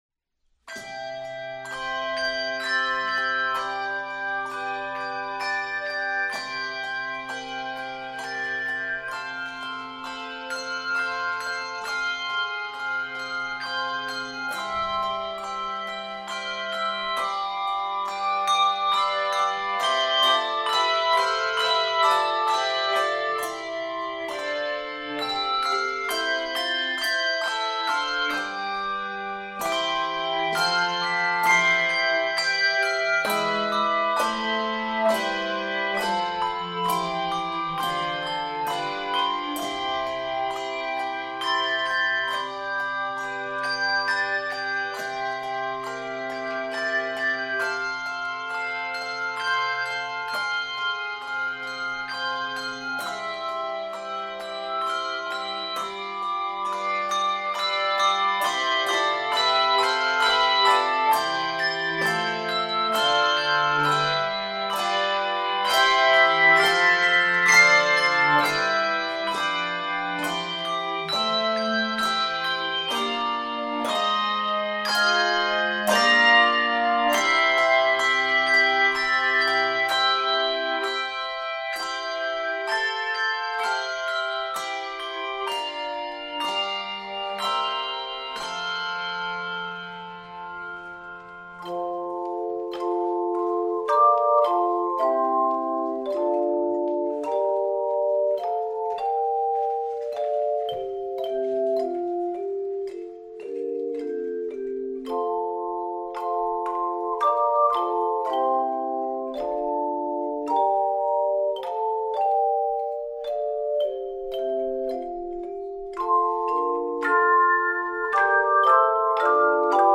Advent/Christmas carols